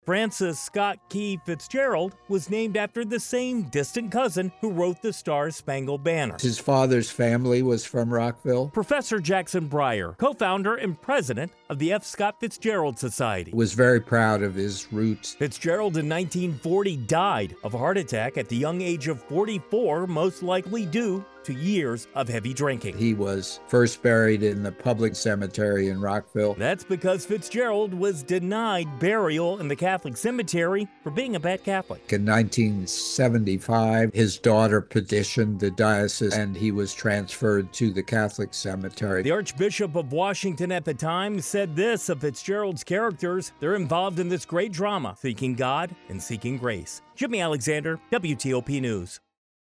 talks to an expert about why F. Scott Fitzgerald's grave is in Rockville, Maryland.